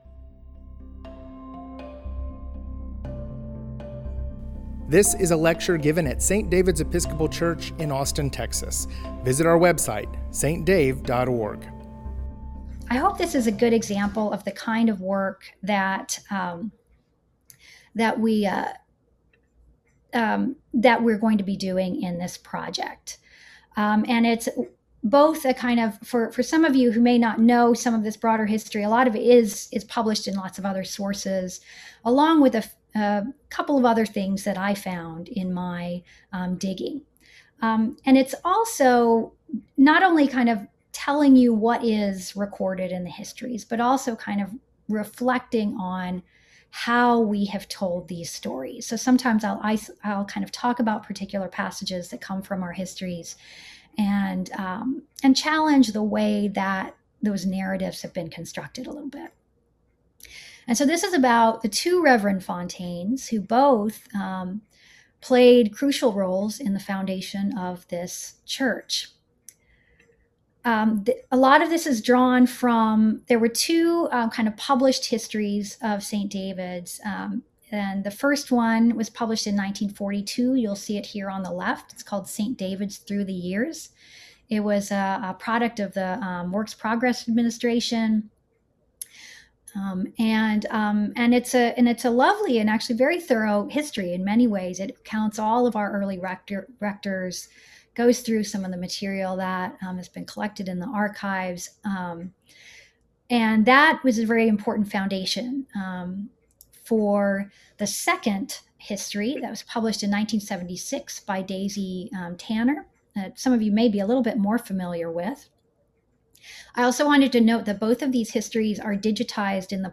St. David's Anti-Racism History Project Lecture Series: The Two Reverends Fontaine (Part 1)